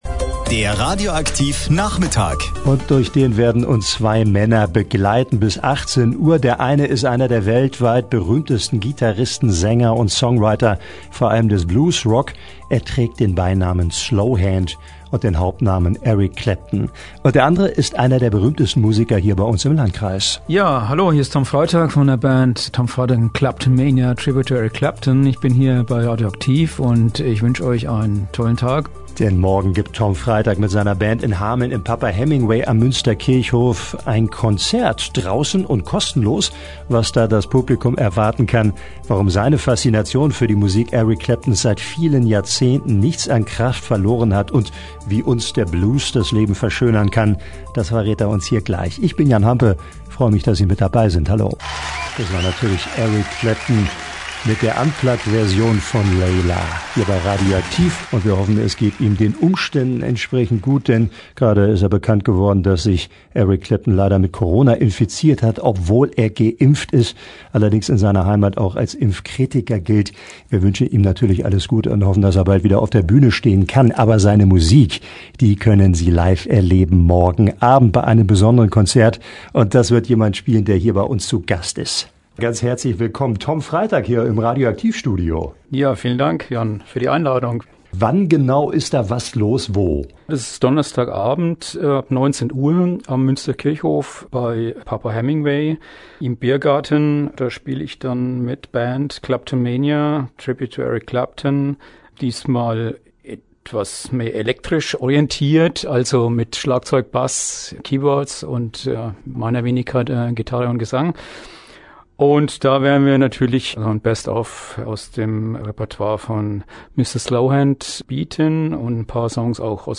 im radio aktiv-Studio